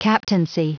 Prononciation du mot captaincy en anglais (fichier audio)
Prononciation du mot : captaincy